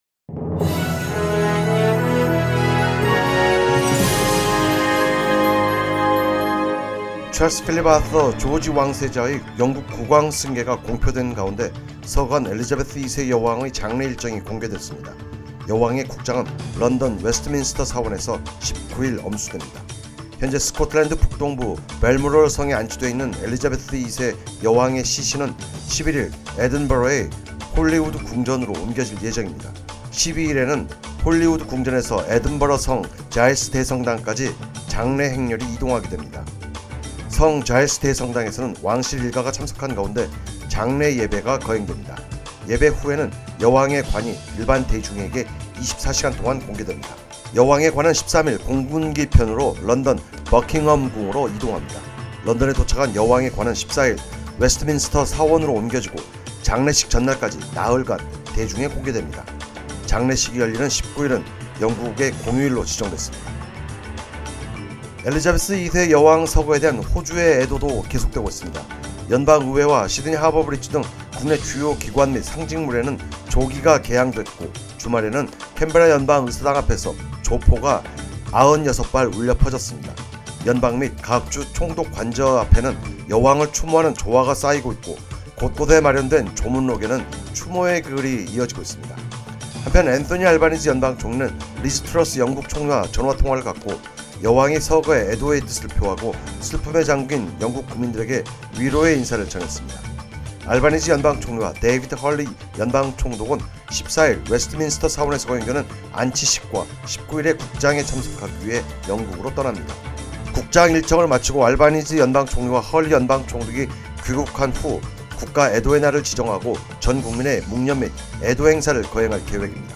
Today's news bulletin Queen’s funeral details revealed as Charles III proclaimed King